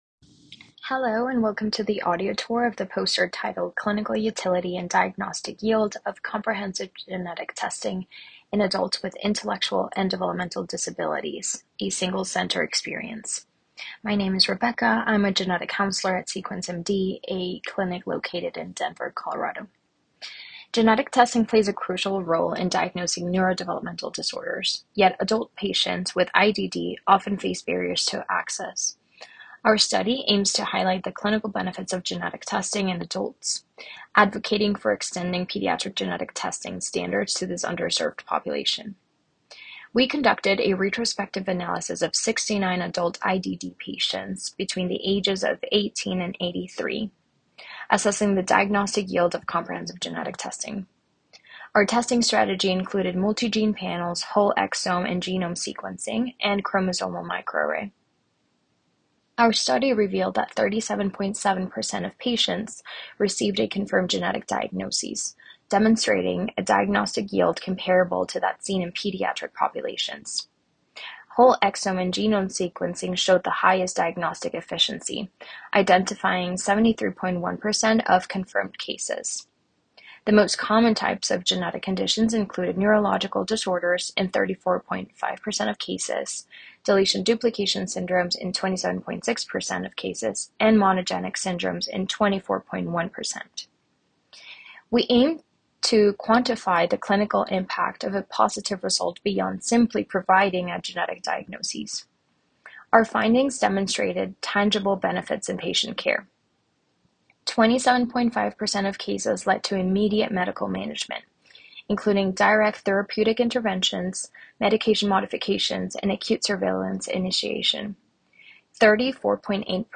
2025 ACMG ANNUAL CLINICAL GENETICS MEETING POSTER PRESENTATION